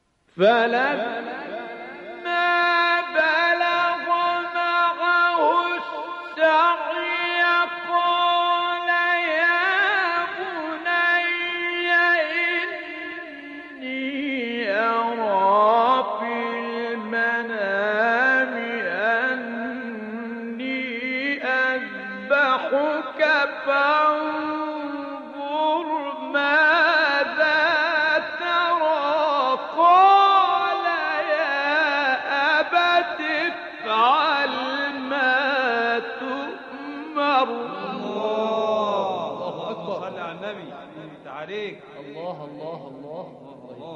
گروه شبکه اجتماعی: مقاطع صوتی از تلاوت‌های قاریان برجسته مصری را می‌شنوید.